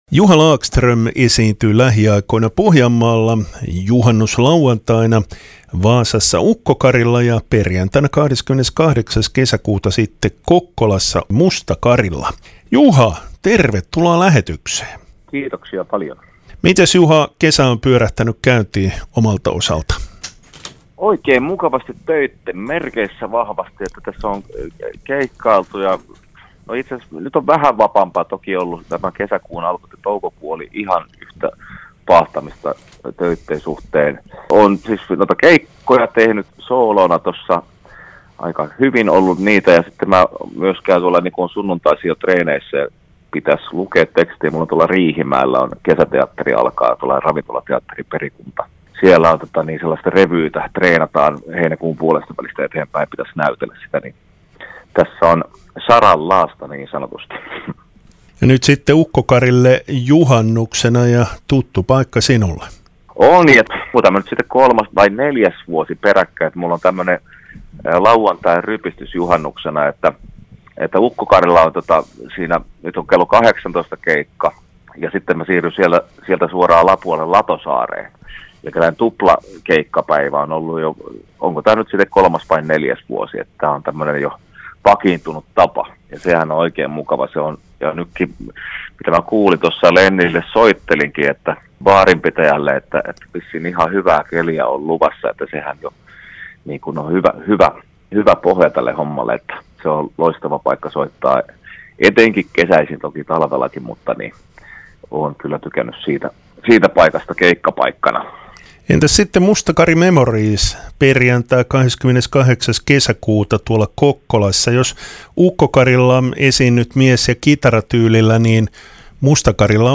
haastattelussa on Juha Lagström